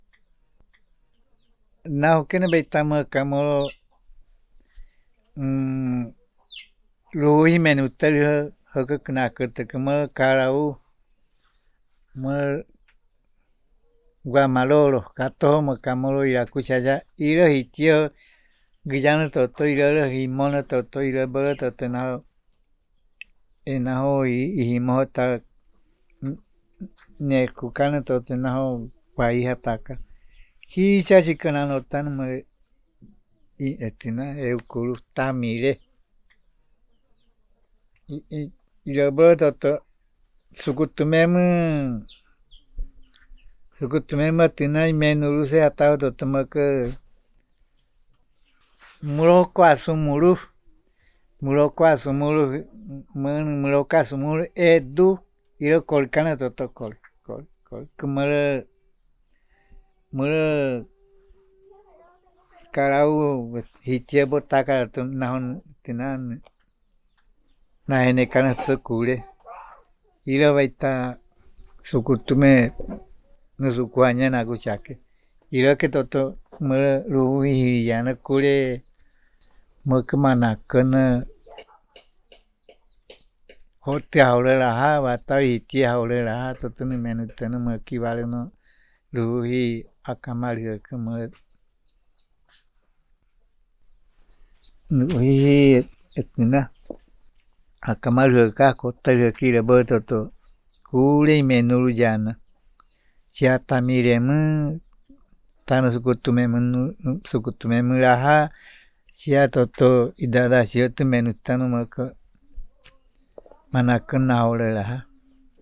Puerto Nare, Guaviare